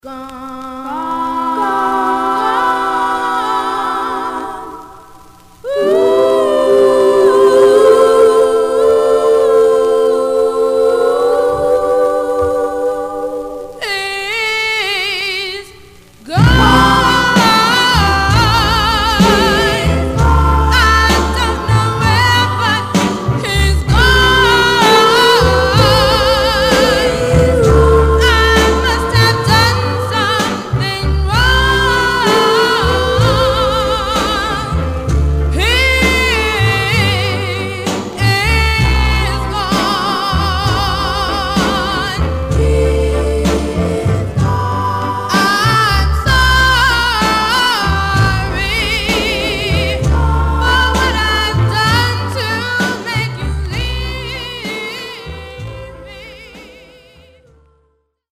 Surface noise/wear
Mono
Black Female Group